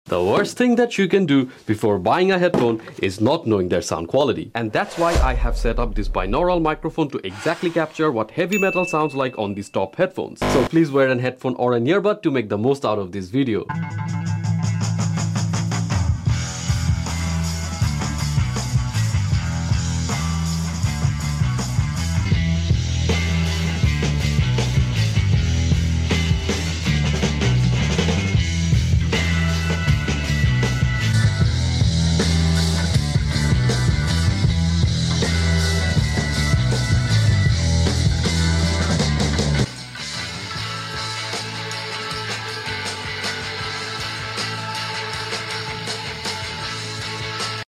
Checking out what Heavy Metal sounds like on top headphones.